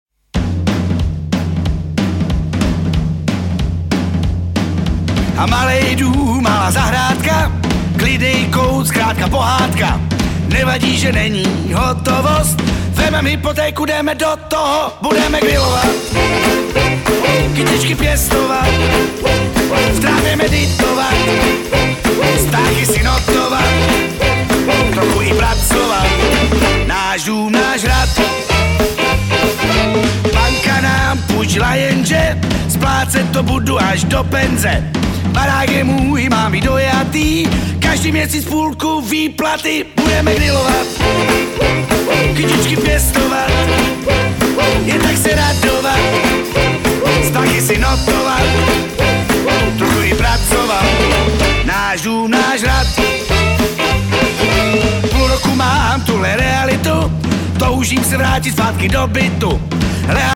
hosty na dechové nástroje.